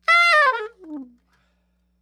SOPRANO FALL
SOP SHRT E 5.wav